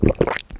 potion_drink.WAV